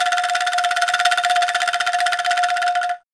Index of /90_sSampleCDs/NorthStar - Global Instruments VOL-2/PRC_Angklungs/PRC_Angklungs